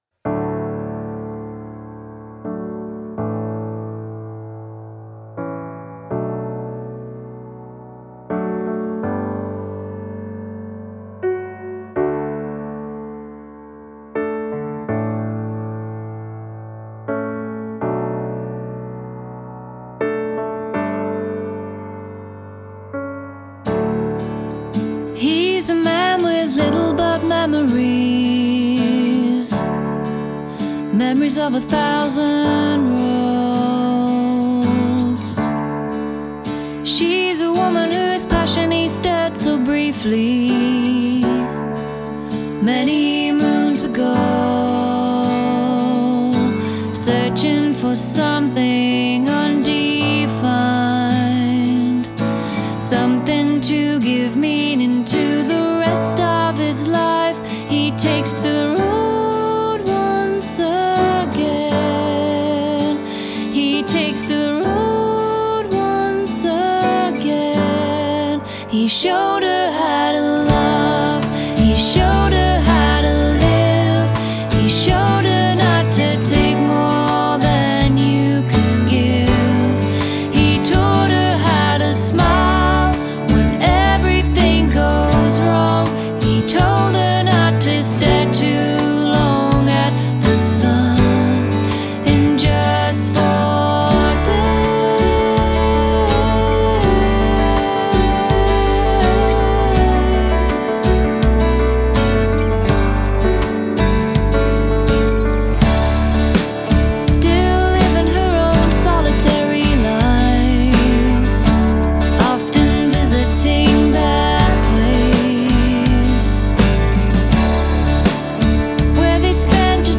Genre: Folk, Singer-Songwriter